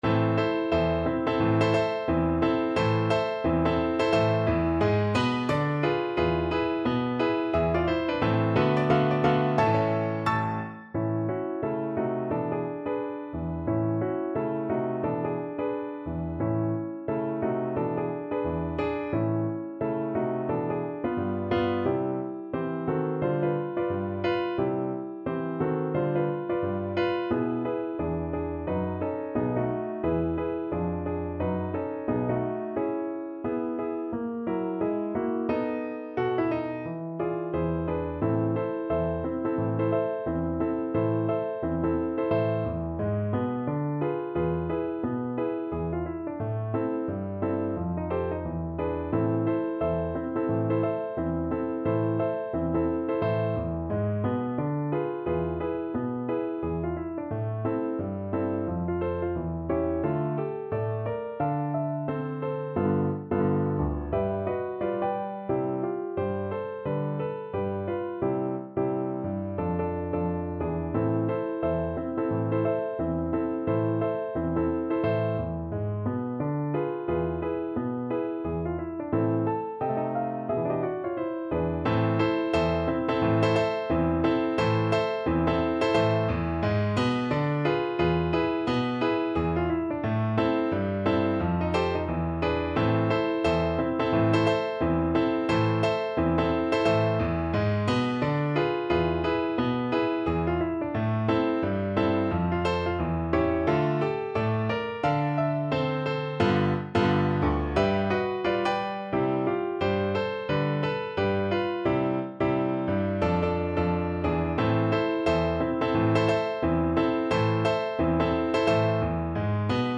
~ = 176 Moderato
Jazz (View more Jazz Violin Music)